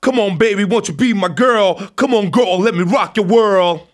RAPHRASE09.wav